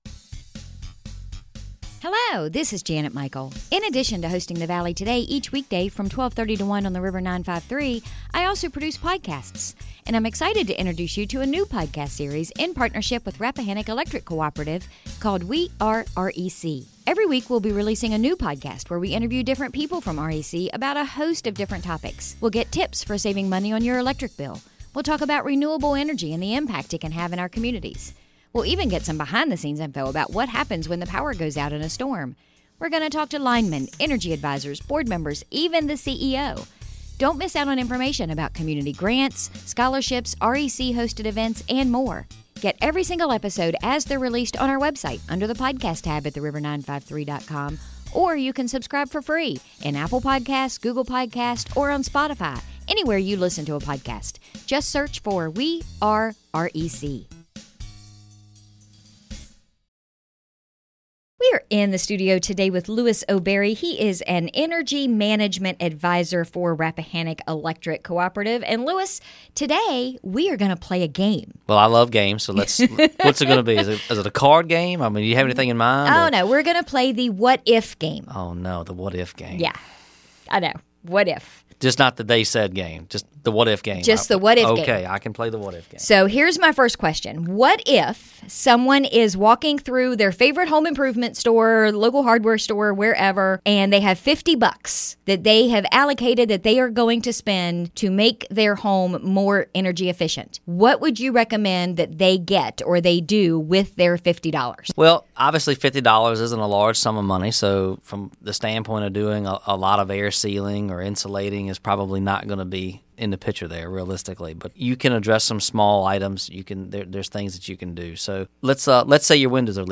Our conversation today